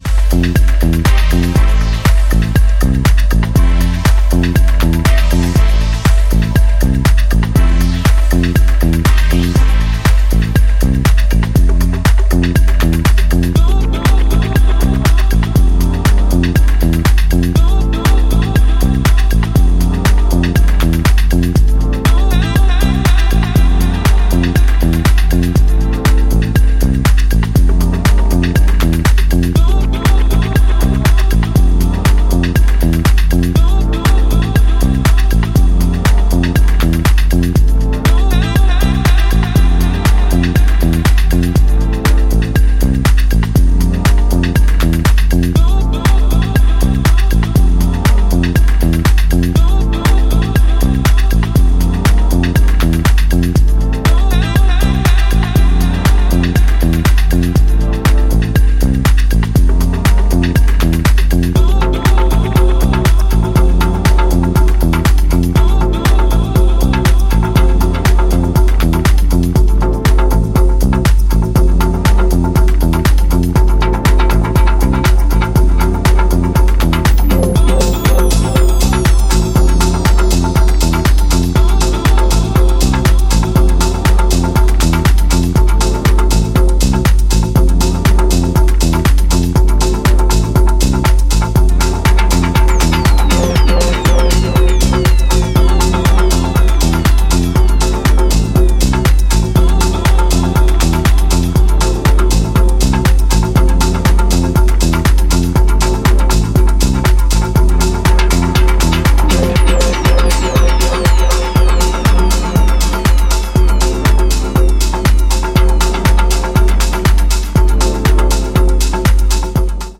重心低くリードするベースラインとエフェクティブな展開にズブっとハマっていく、非常によく出来た仕上がりですね。